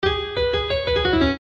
пианино
Короткий клавишный проигрыш